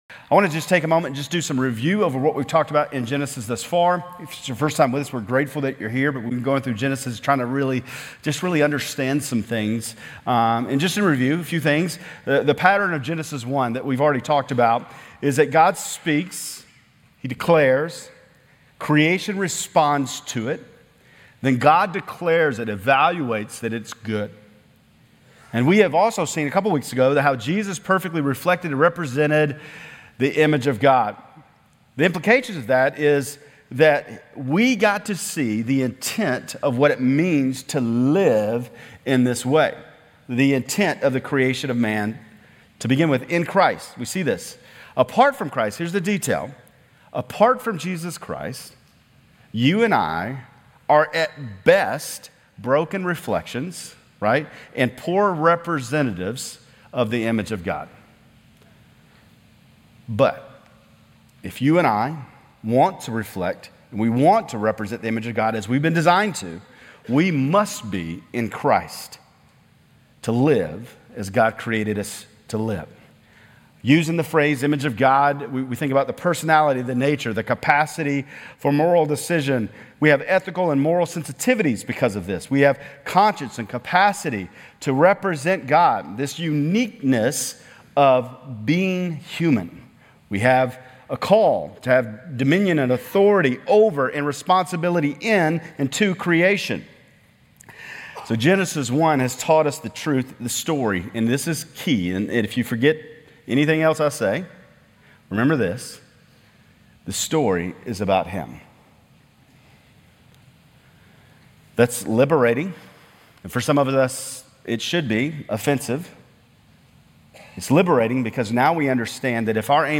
Grace Community Church Lindale Campus Sermons Genesis 1:26-28, 2:21-25 - Gender & Gender Roles Sep 10 2024 | 00:34:26 1x 00:00 / 00:34:26 Subscribe Share RSS Feed Share Link Embed